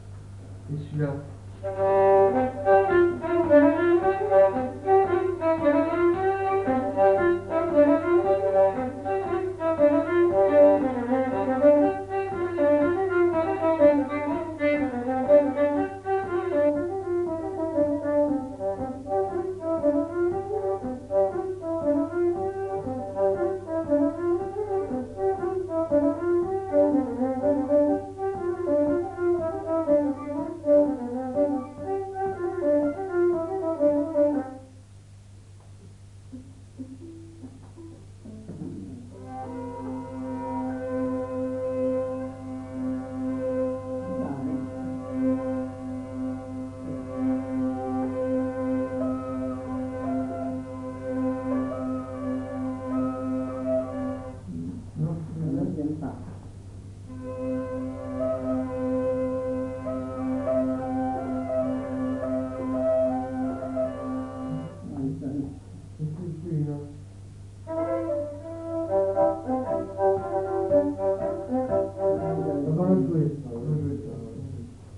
Aire culturelle : Petites-Landes
Lieu : Lencouacq
Genre : morceau instrumental
Instrument de musique : violon
Danse : rondeau